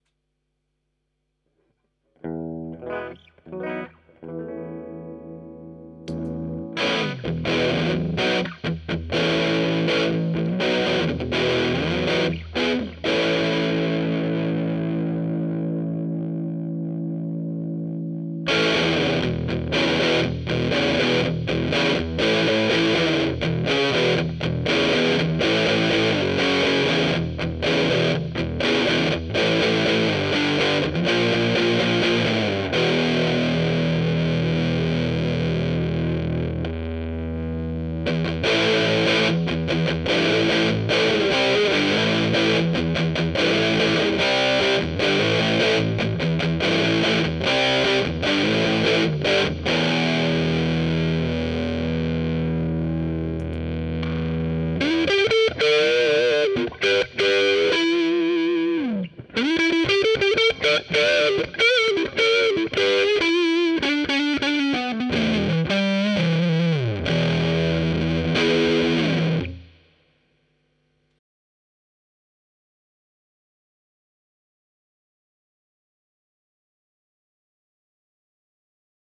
A faithful take on the Way Huge Red Llama 25th Anniversary Edition Overdrive - bringing you everything from gentle, slight ODto full on, in your face distortion.
Recorded with: Fender JA-90 - P90's on neck pickup on clean channel Fender Blues Deluxe